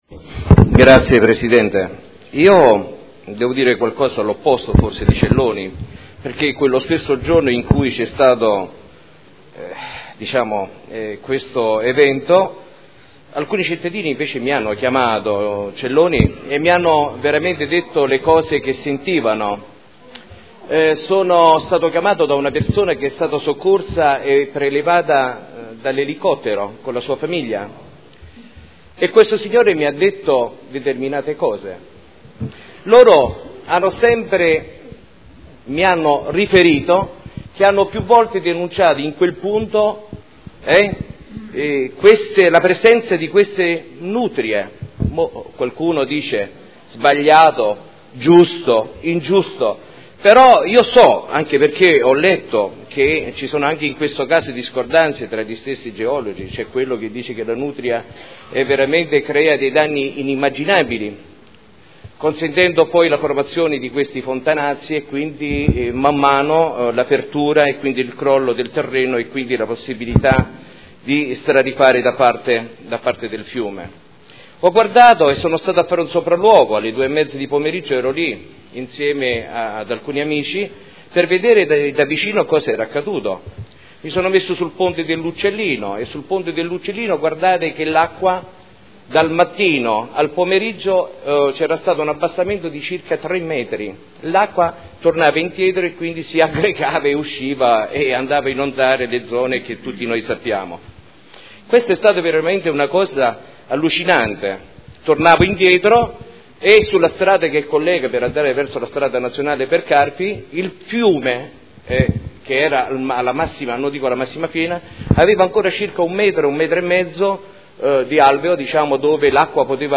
Seduta del 30/01/2014. Dibattito su interrogazioni riguardanti l'esondazione del fiume Secchia.